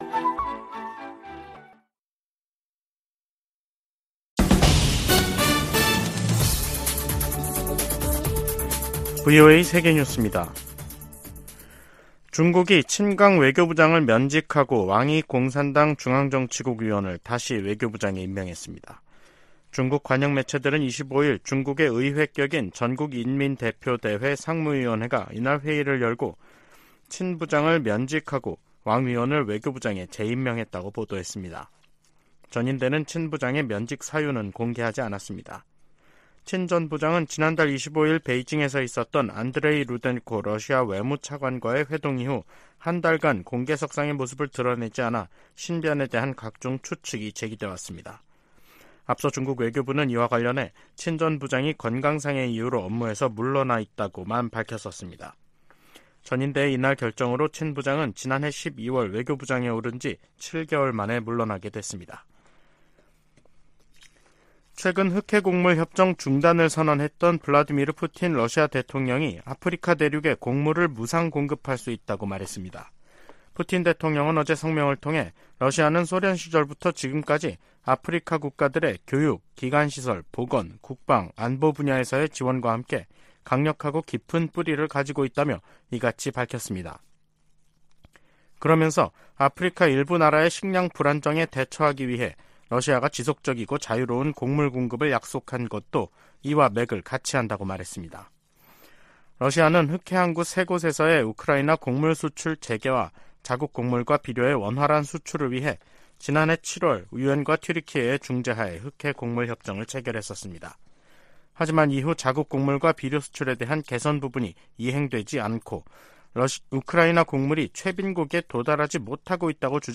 VOA 한국어 간판 뉴스 프로그램 '뉴스 투데이', 2023년 7월 25일 2부 방송입니다. 백악관과 미 국무부는 월북 미군과 관련해 여전히 북한의 응답을 기다리는 중이며, 병사의 안위와 월북 동기 등을 계속 조사하고 있다고 밝혔습니다. 북한이 24일 탄도미사일 2발을 동해상으로 발사했습니다.